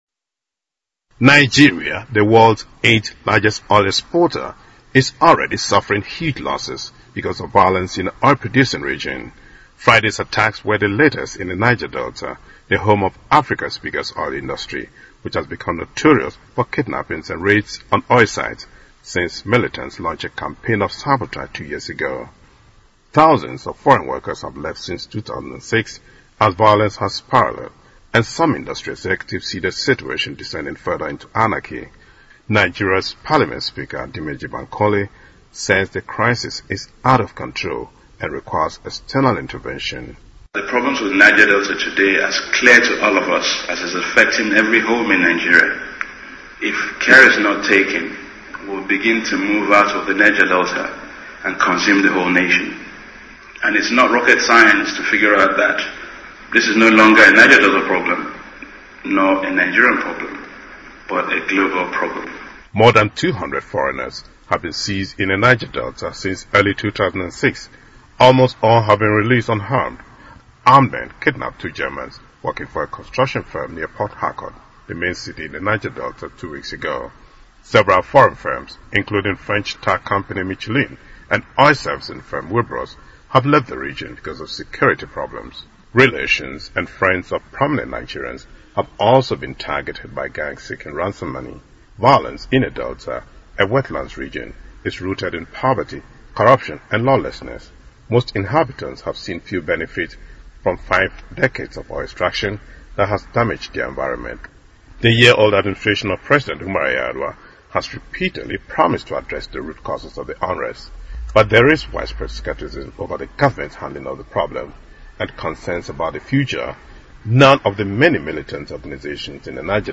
位置：首页 > 英语听力 > 英语听力教程 > 英语新闻听力